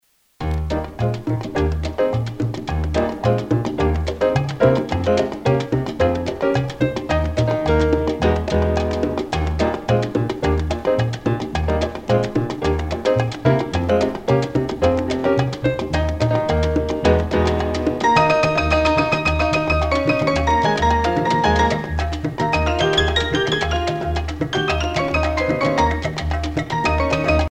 danse : mambo
Pièce musicale éditée